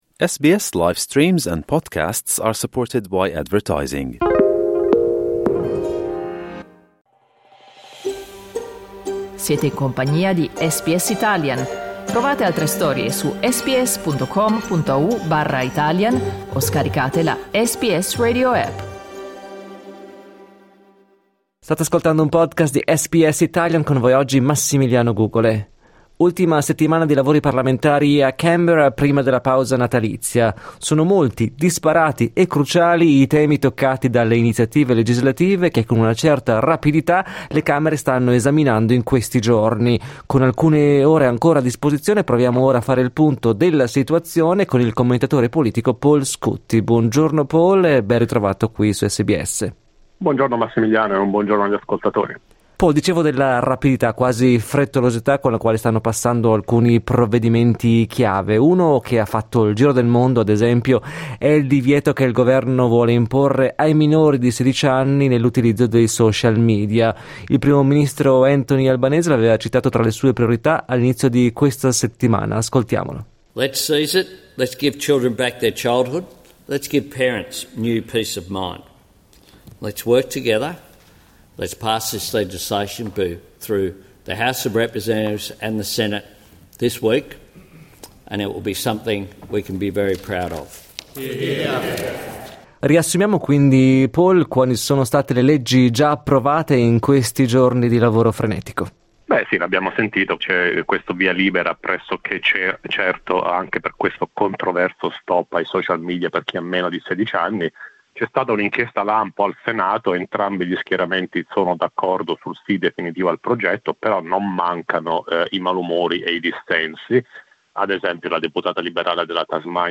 … continue reading 1 Giornale radio giovedì 28 novembre 2024 12:18 Play Pause 3h ago 12:18 Play Pause Main Kemudian Main Kemudian Senarai Suka Disukai 12:18 Il notiziario di SBS in italiano.